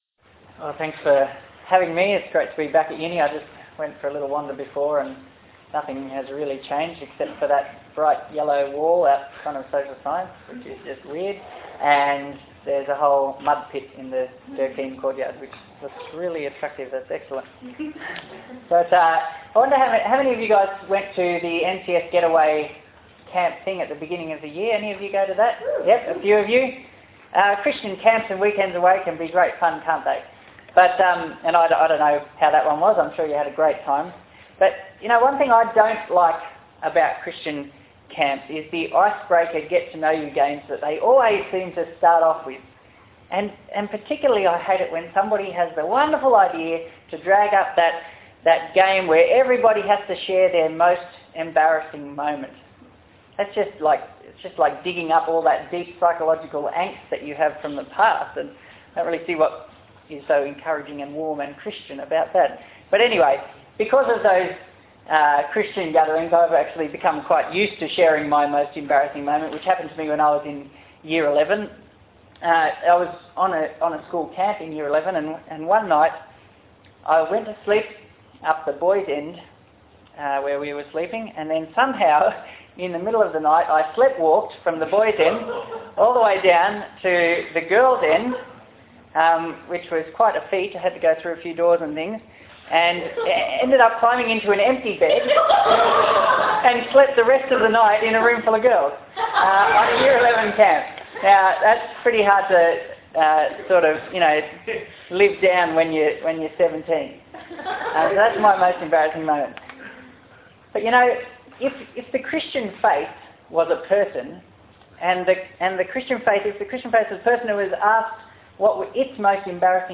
Passage: Luke 24:36-53 Talk Type: Bible Talk « Atonement